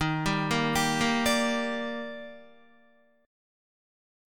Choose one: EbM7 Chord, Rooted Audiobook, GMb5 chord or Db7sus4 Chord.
EbM7 Chord